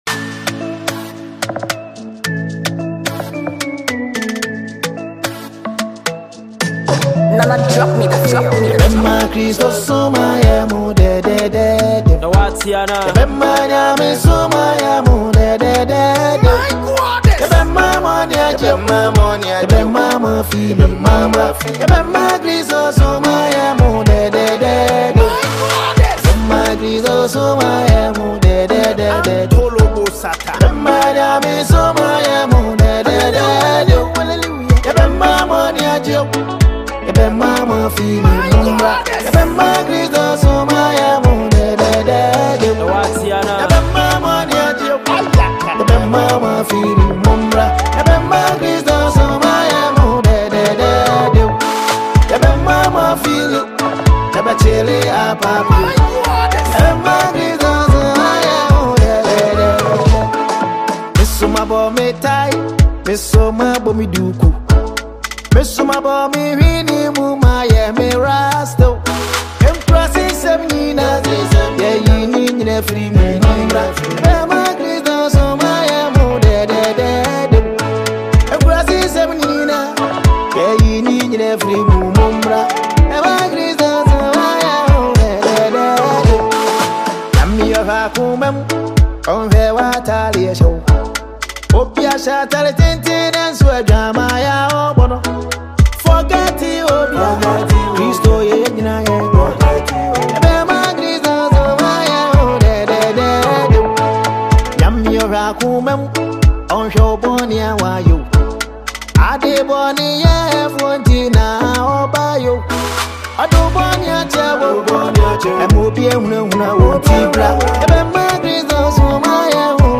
Gospel
uplifting tune
vibrant rhythms and heartfelt lyrics
lively gospel vibe